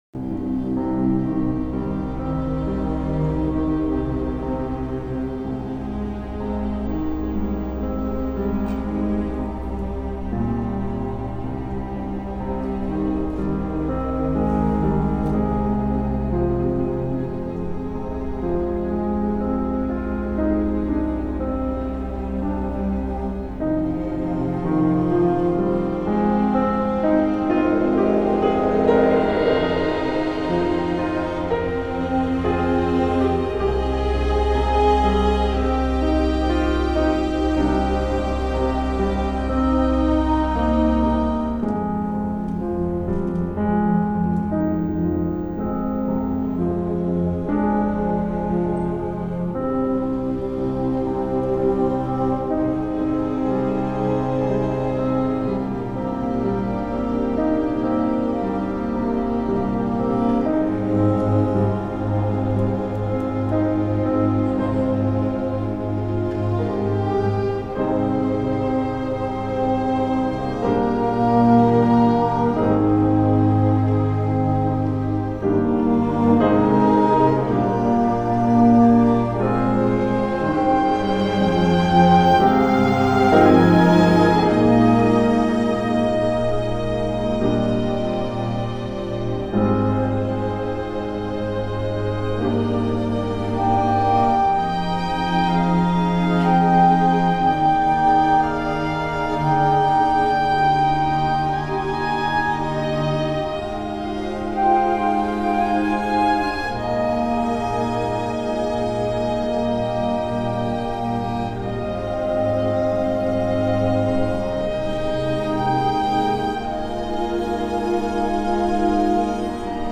score for chamber orchestra